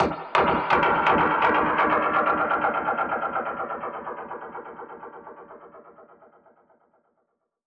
Index of /musicradar/dub-percussion-samples/125bpm
DPFX_PercHit_E_125-04.wav